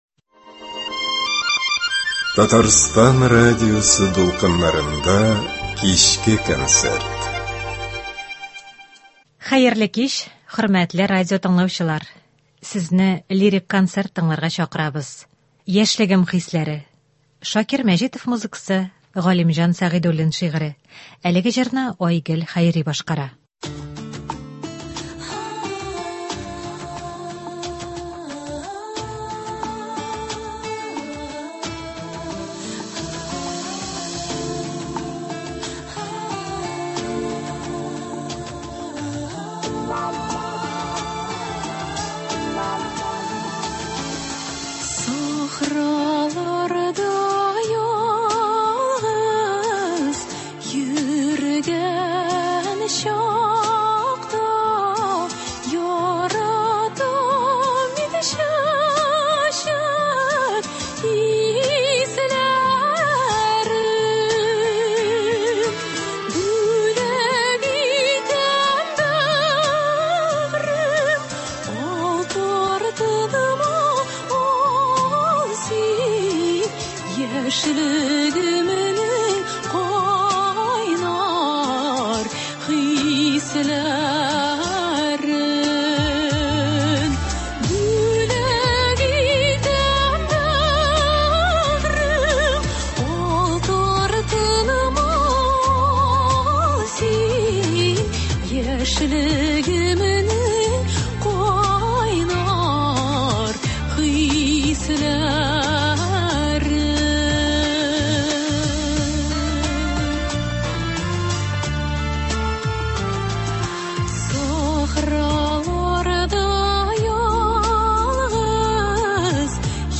Лирик музыка концерты.